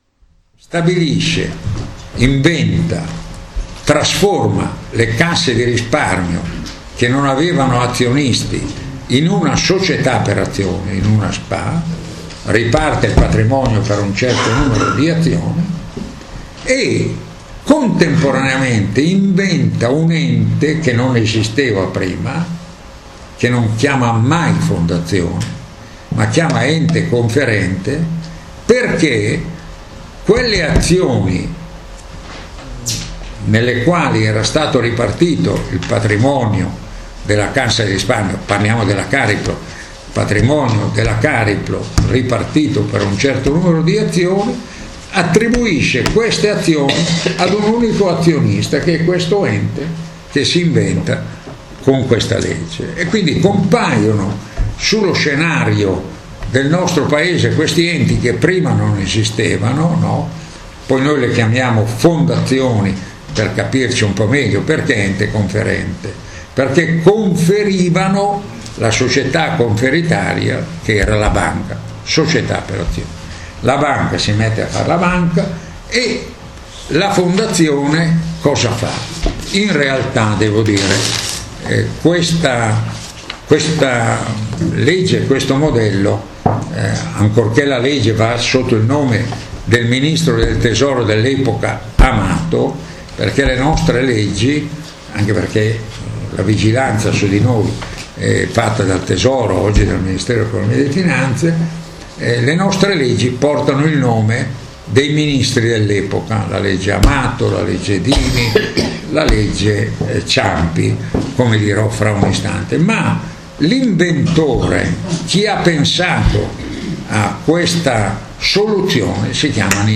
Le politiche culturali per lo sviluppo del territorio. Intervento di Giuseppe Guzzetti al Corso di Formazione alla politica 2013-2014 dei Circoli Dossetti.